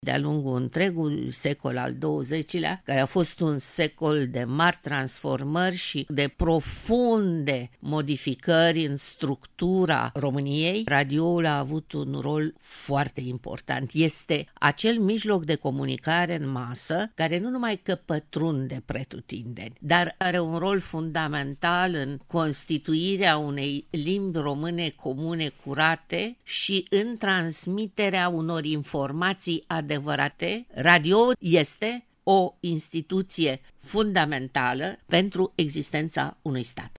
Invitată la Radio România Actualităţi